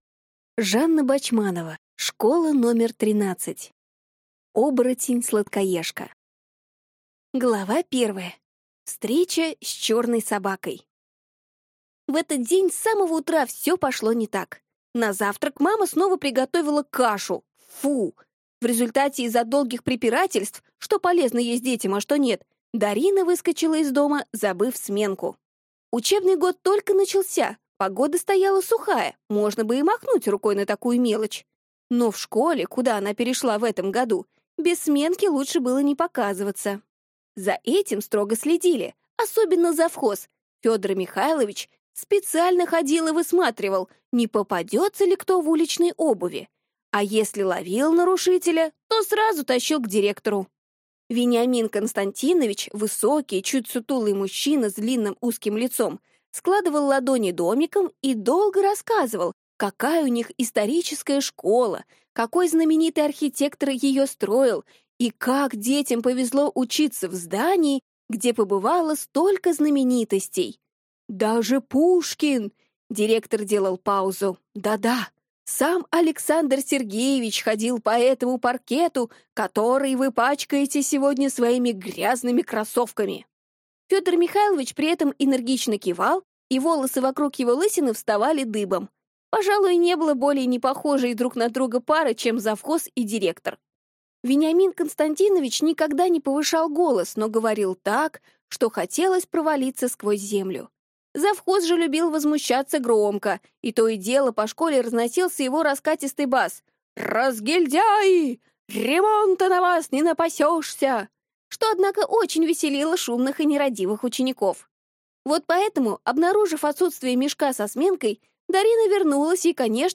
Аудиокнига Школа №13. Оборотень-сладкоежка | Библиотека аудиокниг